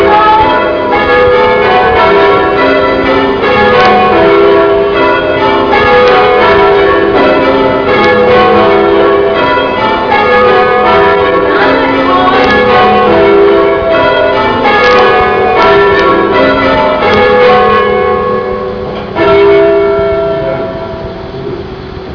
bells_1_.wav